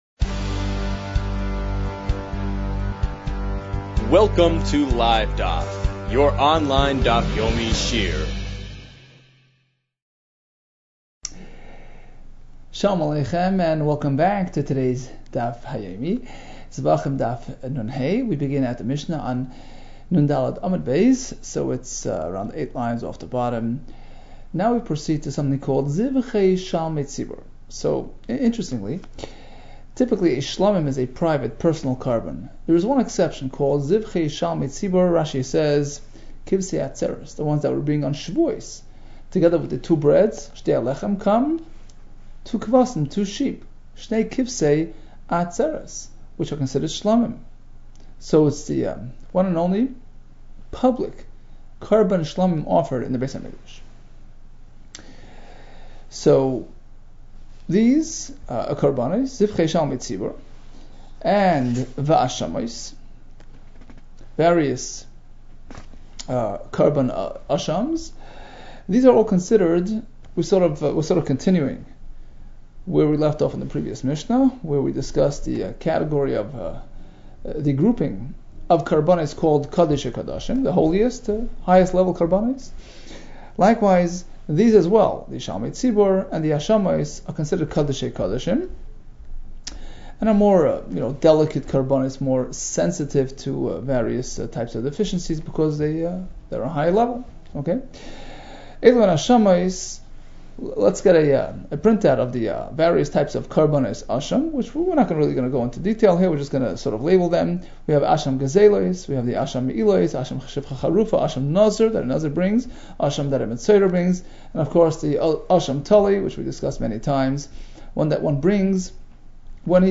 Zevachim 55 - זבחים נה | Daf Yomi Online Shiur | Livedaf